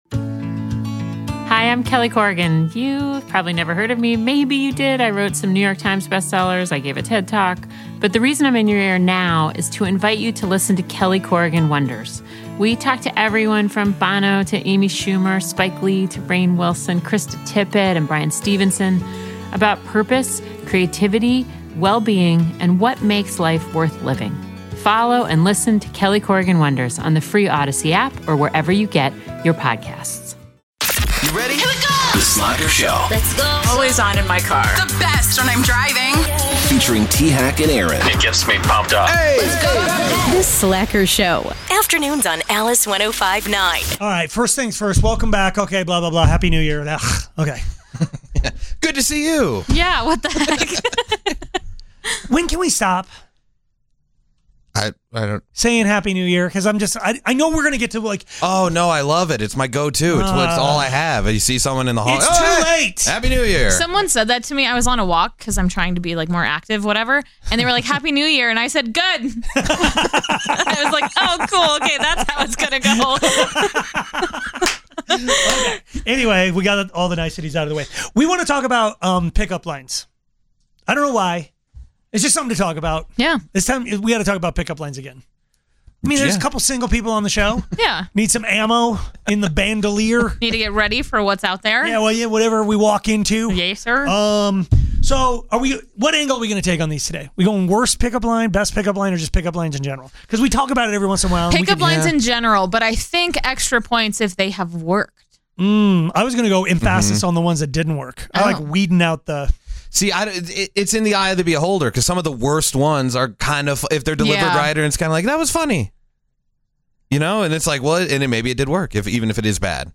Indulge your inner curiosity with caller driven conversation that makes you feel like you’re part of the conversation—or even better, eavesdropping on someone else’s drama.